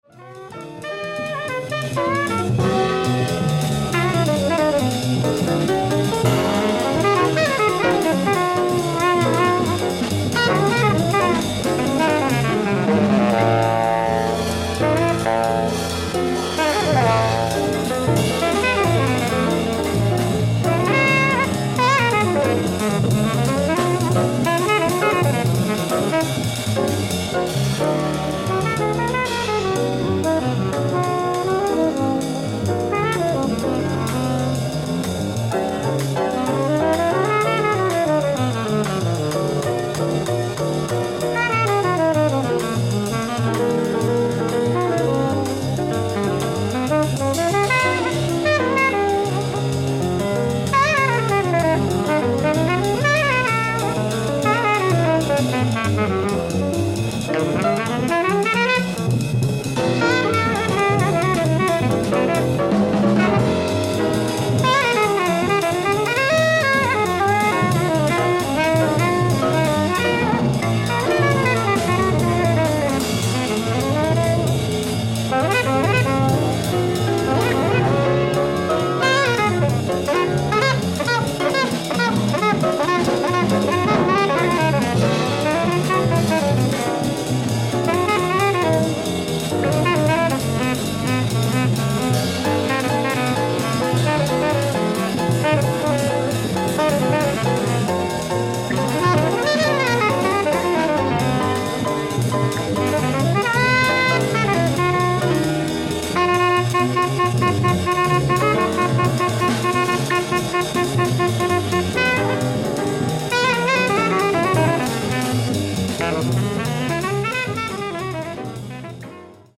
ライブ・アット・ウースター、マサチュウセッツ 94/21/1972
※試聴用に実際より音質を落としています。